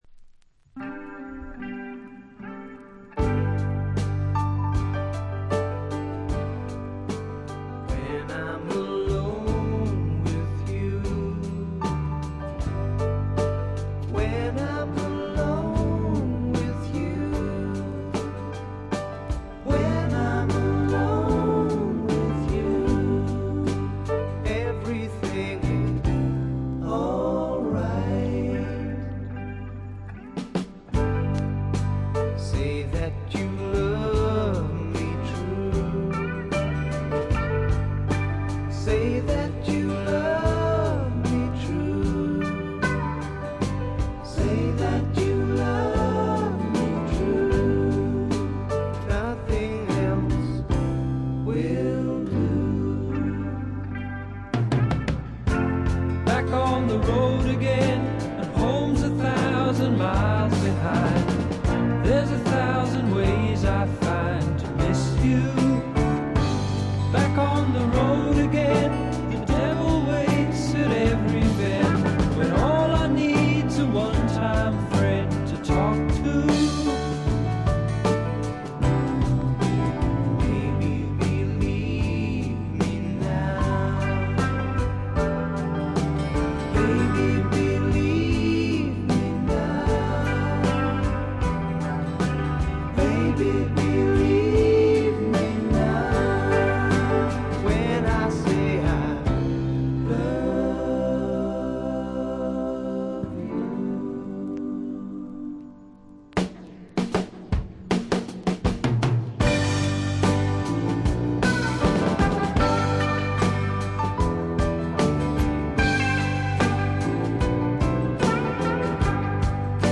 渋い英国産スワンプ、理想的な「イギリスのアメリカ」！